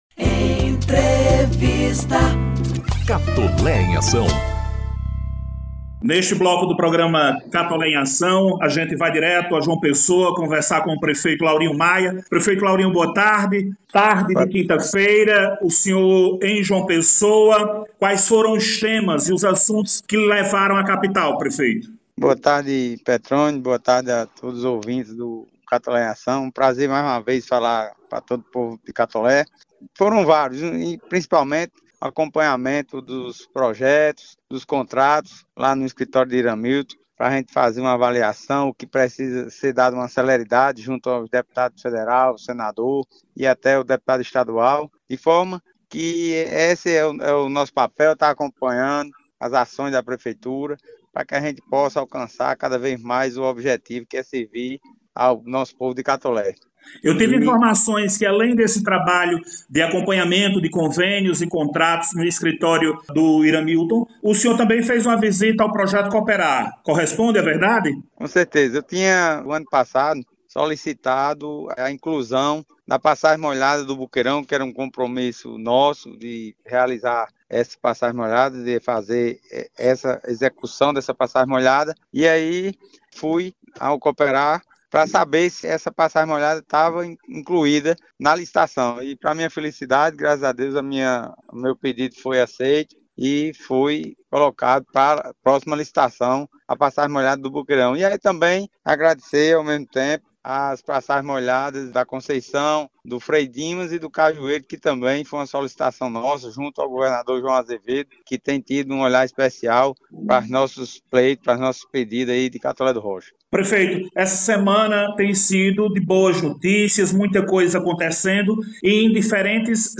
O prefeito Laurinho Maia – Catolé do Rocha – participou, sexta-feira (01/03), do programa institucional do município, ocasião em que falou da recente viagem à João Pessoa.